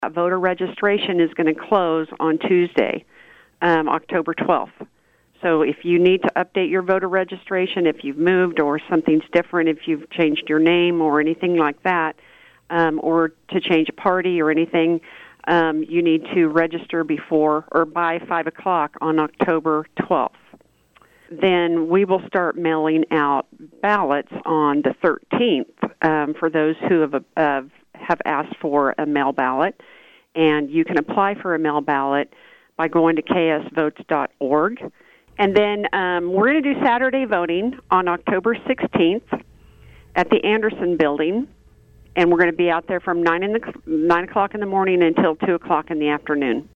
With just over a month to go until the general election, Lyon County Clerk Tammy Vopat recently joined KVOE’s Newsmaker 2 segment to make sure everyone is aware of some important upcoming dates.